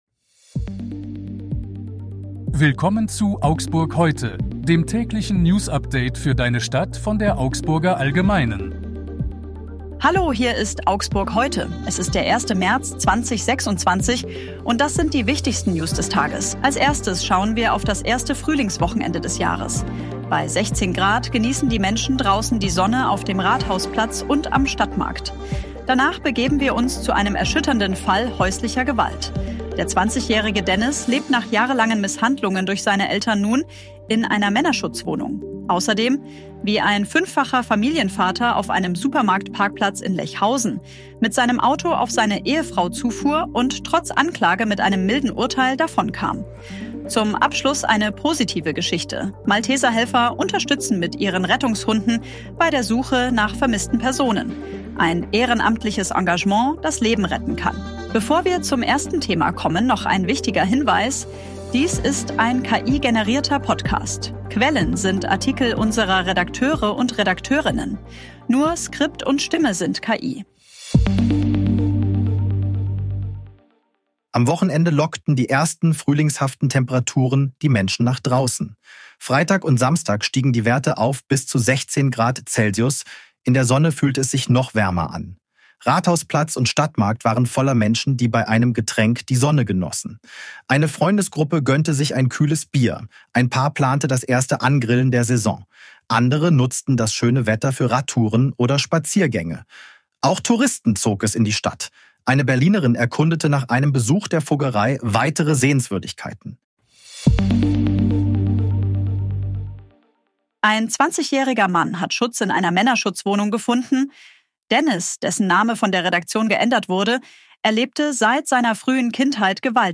der Suche nach Menschen Dies ist ein KI-generierter Podcast.
Skript und Stimme sind KI.